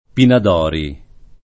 [ pinad 0 ri ]